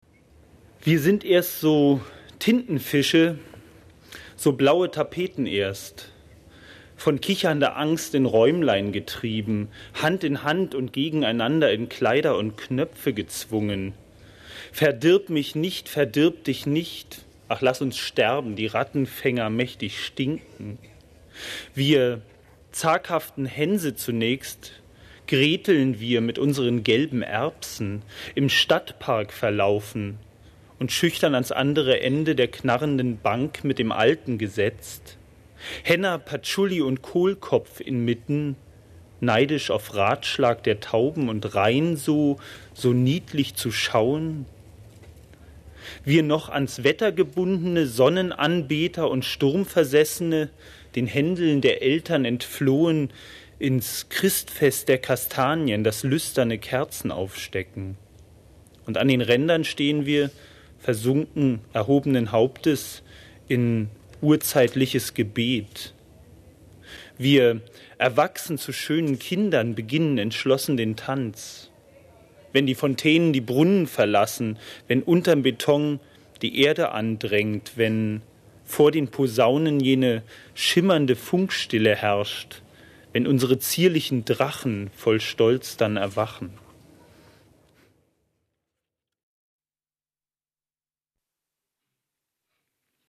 Lesung von Uwe Kolbe in der literaturWERKstatt Berlin zur Sommernacht der Lyrik – Gedichte von heute